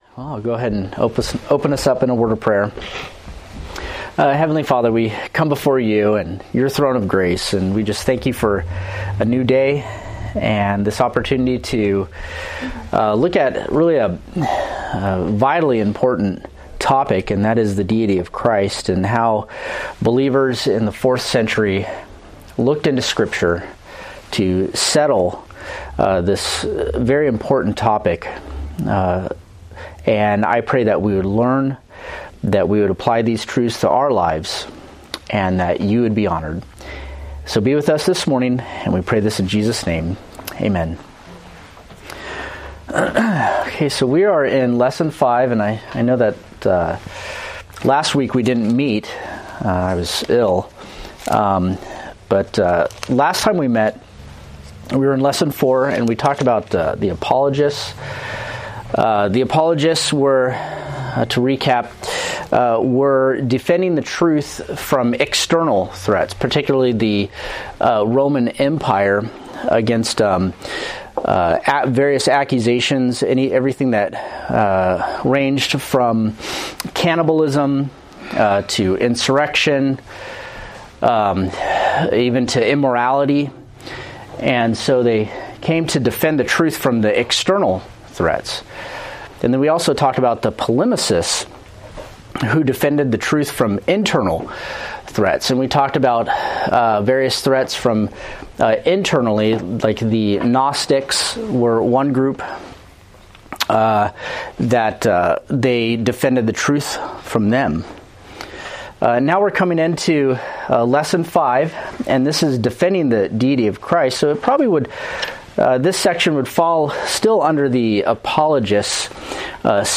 Date: Feb 23, 2025 Series: Forerunners of the Faith Grouping: Sunday School (Adult) More: Download MP3